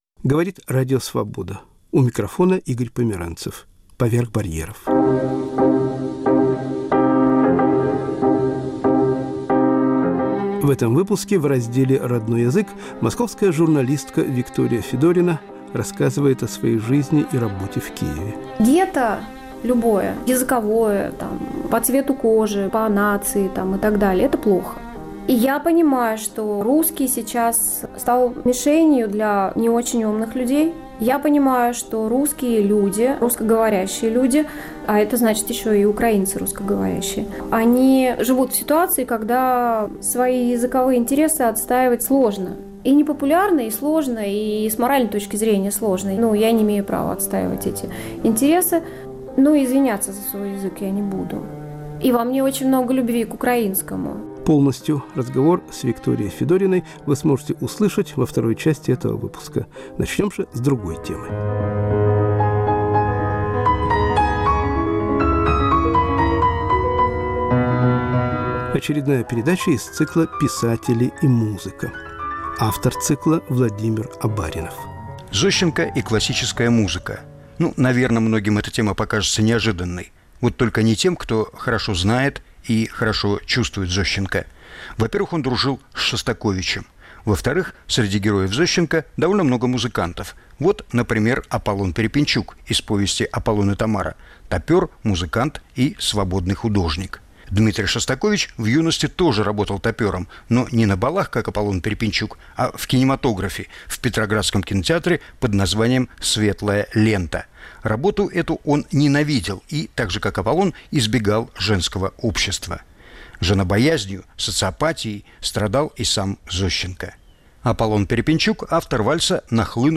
*** Деревенские старухи о жизни на том и этом свете.